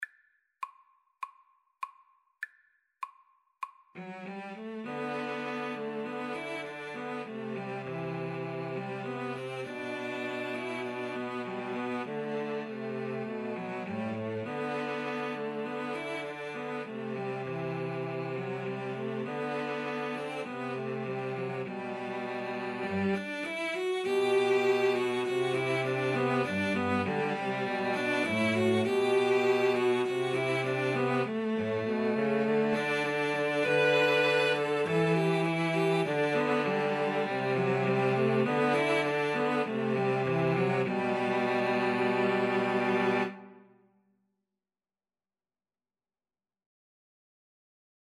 G major (Sounding Pitch) (View more G major Music for Cello Trio )
Espressivo
Cello Trio  (View more Intermediate Cello Trio Music)